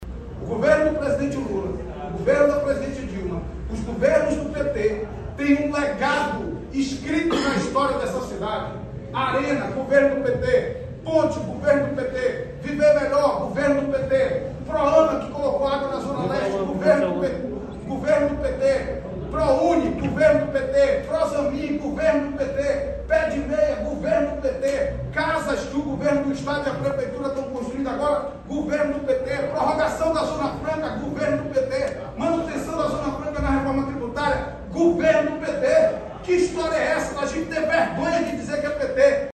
Marcelo Ramos também criticou o ex-presidente Jair Bolsonaro e relembrou episódios como a crise do oxigênio e as séries de medidas que atingiram a Zona Franca de Manaus (ZFM). No discurso destacou obras dos governo do PT realizadas no Amazonas: